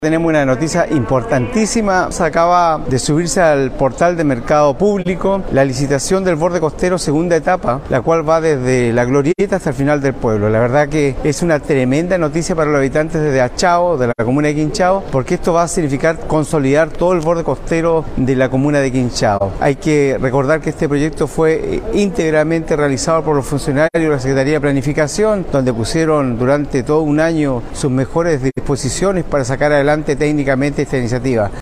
Al respecto, el jefe comunal quinchaíno sostuvo que, gracias al trabajo del equipo de la Secretaría Comunal de Planificación, SECPLAN, el proyecto pudo tomar vida luego de haber sido postergado por varios años: